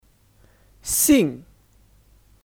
姓 Xìng (Kata benda): Marga Contoh kalimat:你姓什么 Nǐ xìng shénme?